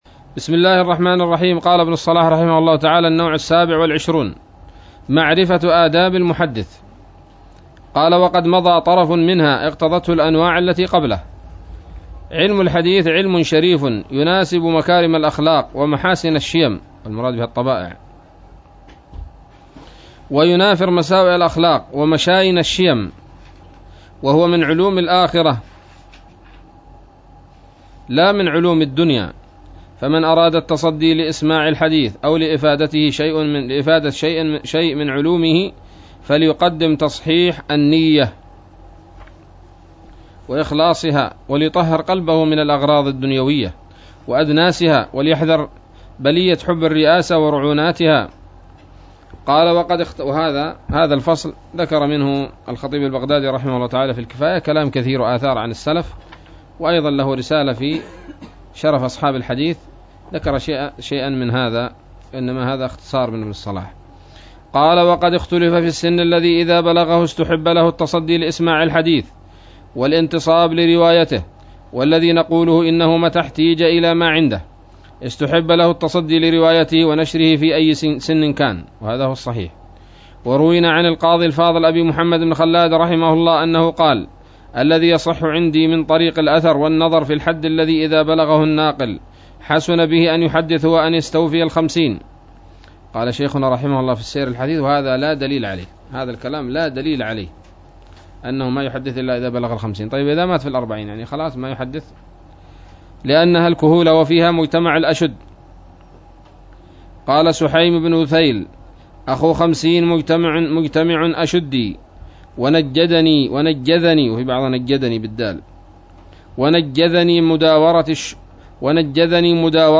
الدرس الرابع والثمانون من مقدمة ابن الصلاح رحمه الله تعالى